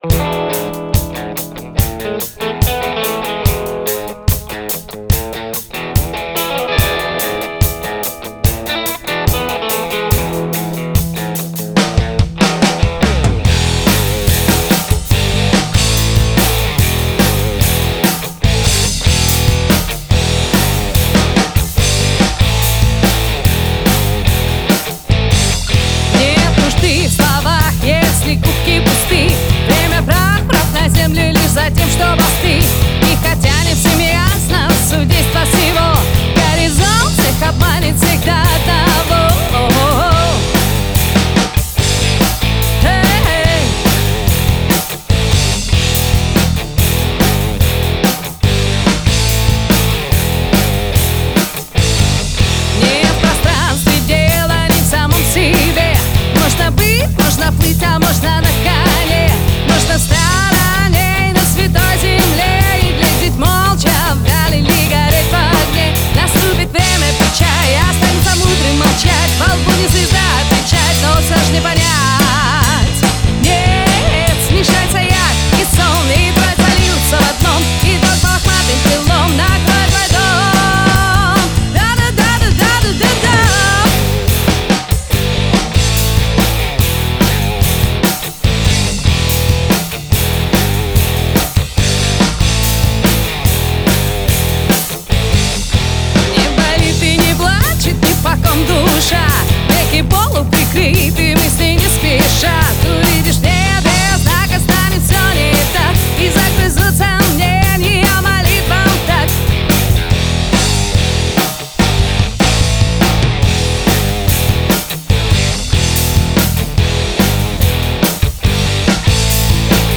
Живой хардовый рок-н-ролл, в правильном ли направлении двигаюсь?
То, что в вокале пару мест поправить нужно, гитара кое-где немного с ритма съезжает - сам слышу.